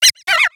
Cri de Ratentif dans Pokémon X et Y.